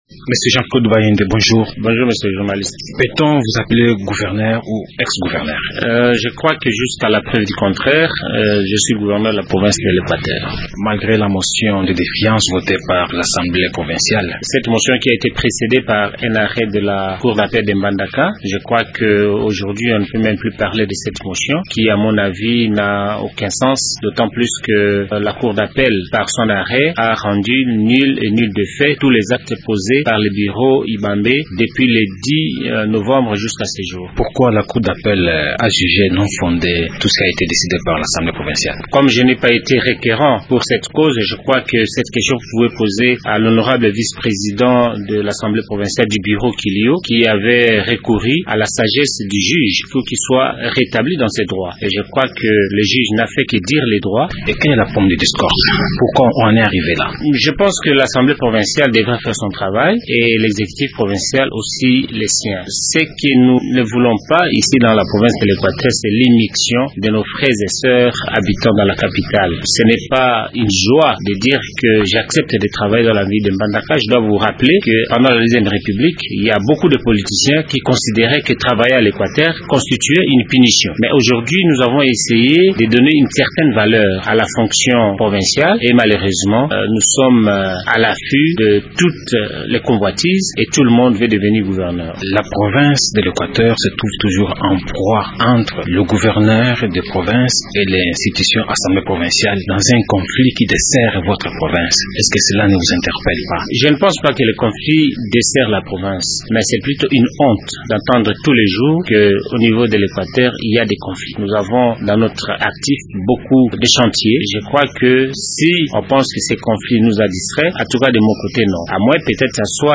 Dans cet entretien, le gouverneur de l’Equateur fait sa lecture de ce conflit.